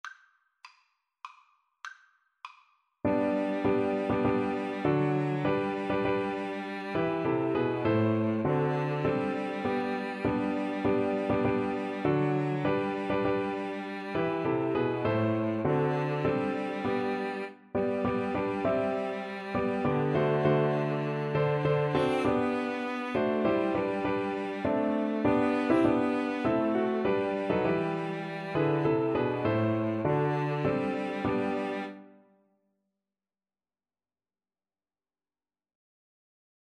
Free Sheet music for Piano Trio
G major (Sounding Pitch) (View more G major Music for Piano Trio )
3/4 (View more 3/4 Music)
Traditional (View more Traditional Piano Trio Music)